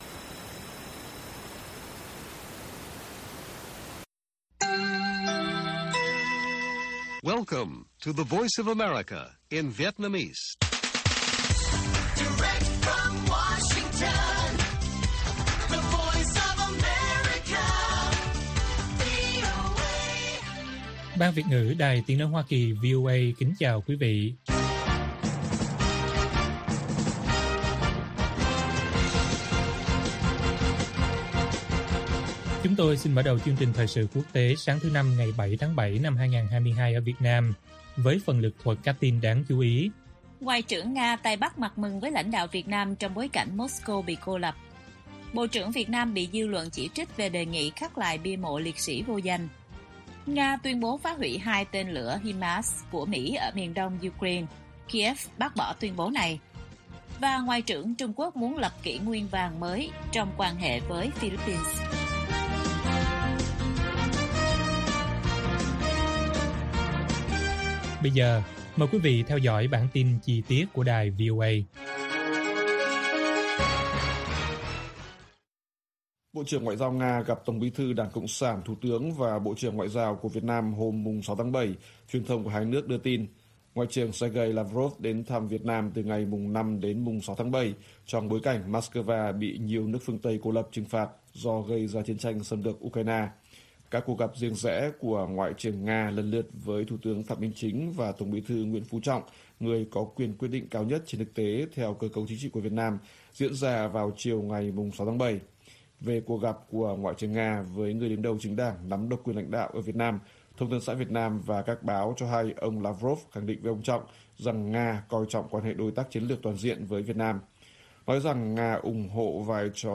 Nga tuyên bố phá hủy hai tên lửa HIMARS của Mỹ ở đông Ukraine, Kyiv bác bỏ - Bản tin VOA